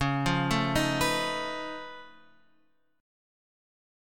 C#+9 chord {9 8 7 8 x 7} chord